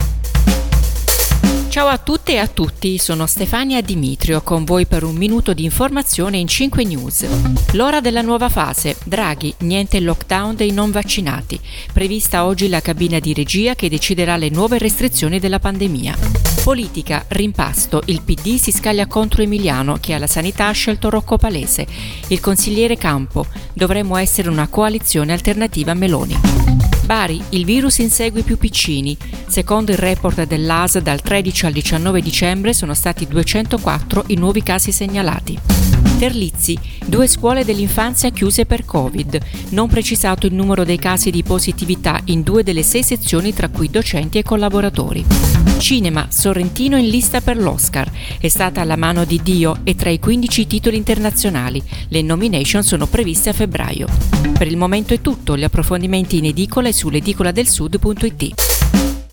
Giornale radio